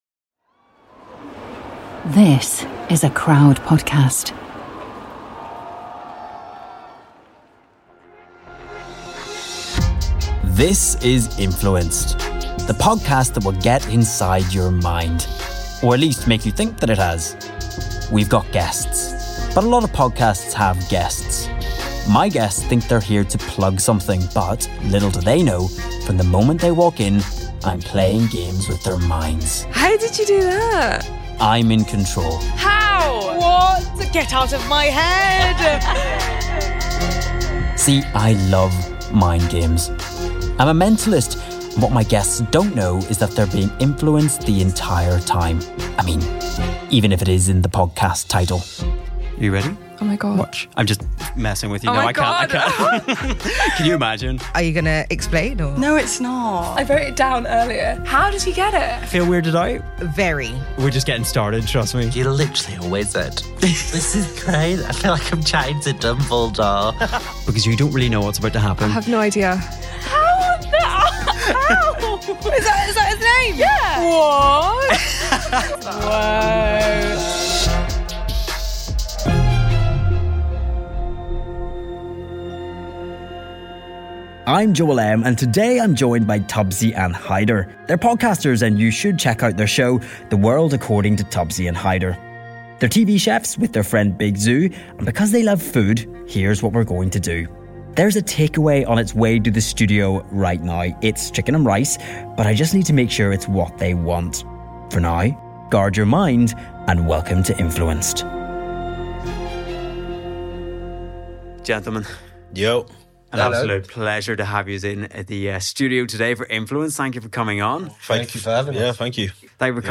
So as we know they love food we thought we would order a takeaway to the studio, but here's the trick we're going to "Influence" them into picking the specific food we've already ordered.